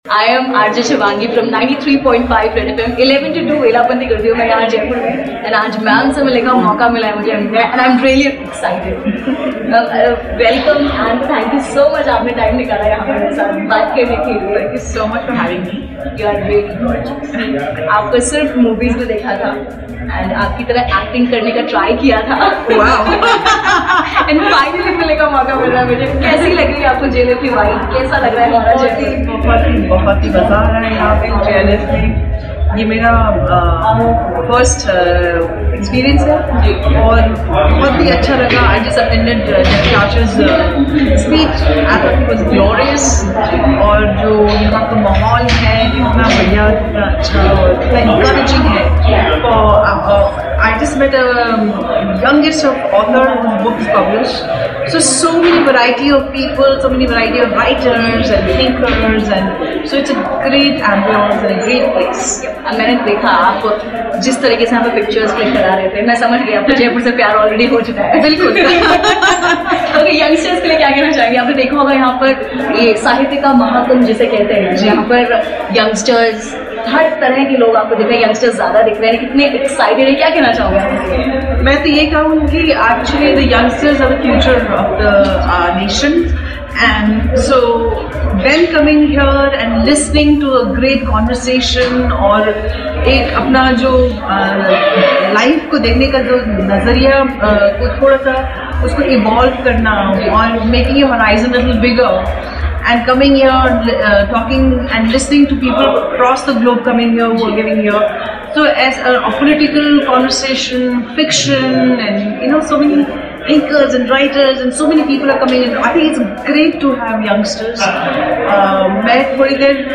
in Exclusive conversation with Manisha Koirala - Vellapanti Dot Com Jaipur Literature Festival